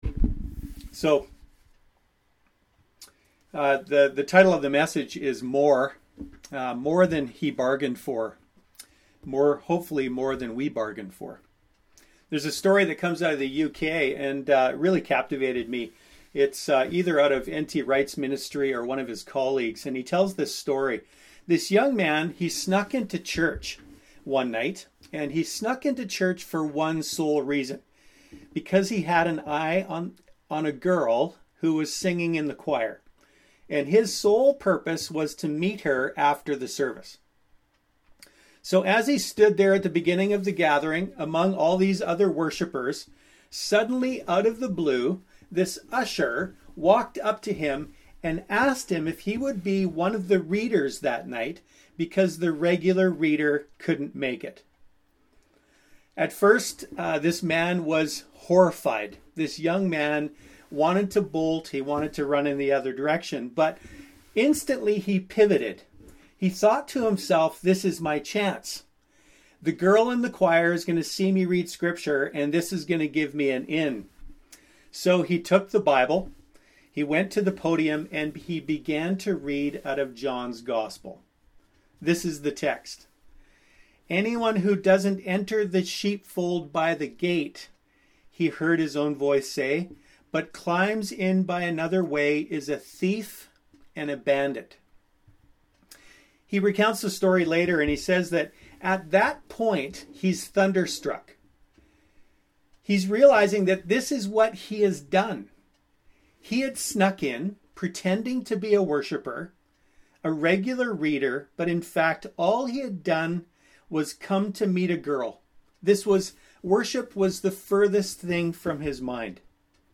Sermons | The River Church